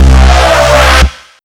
Desecrated bass hit 06.wav